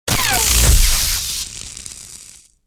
shieldDeflect.wav